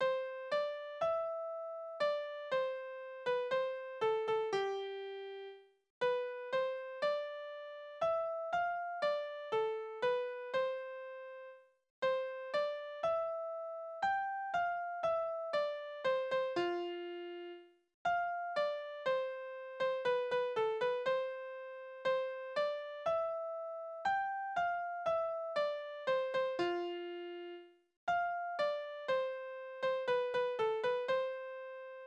Balladen: Der junge Bräutigam und seine frühere Liebste
Tonart: C-Dur
Taktart: 4/4
Tonumfang: Oktave
Besetzung: vokal